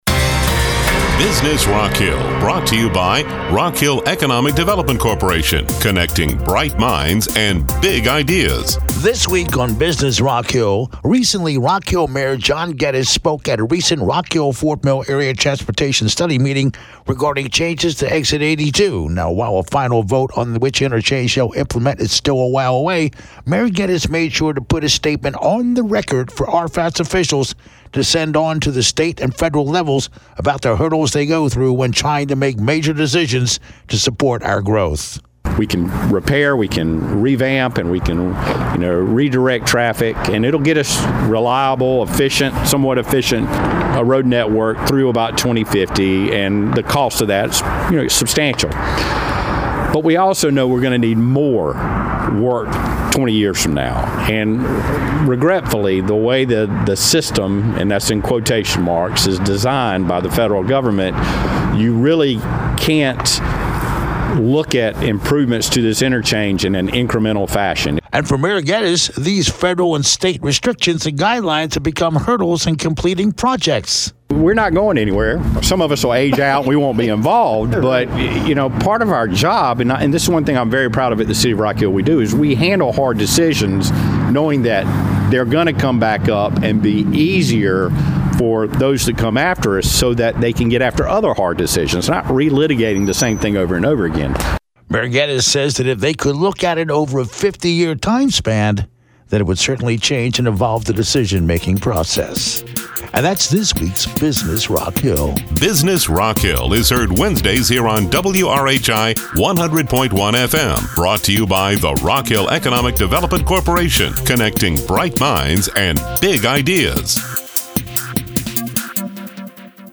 This week on Business Rock Hill, recently Rock Hill Mayor John Gettys spoke at an RFATS meeting put a statement on the record for RFATS officials to send up to the state and federal level about the hurdles they go through when trying to make major decisions to support our growth.